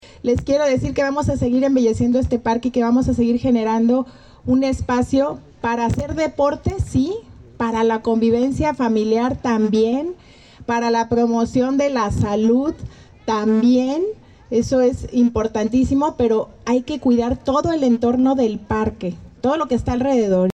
Lorena Alfaro García, presidenta municipal